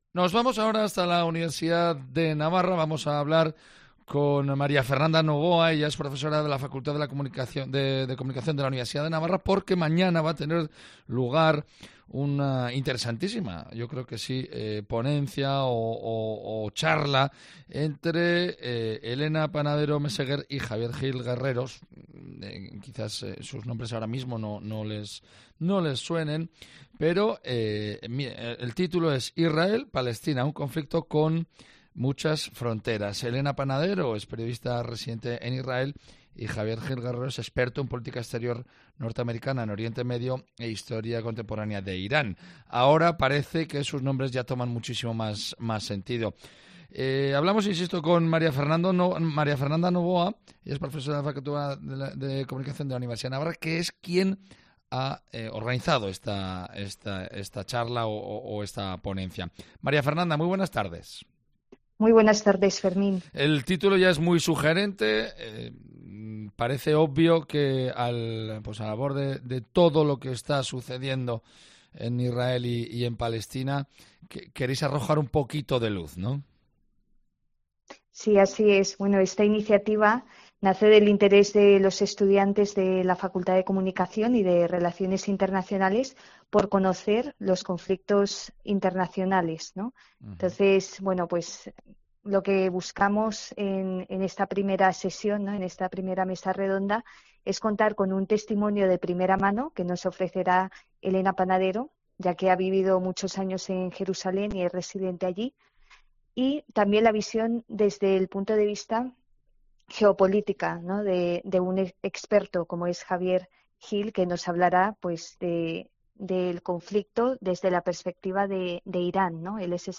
Mesa redonda: Israel-Palestina